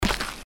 stepdirt_1.wav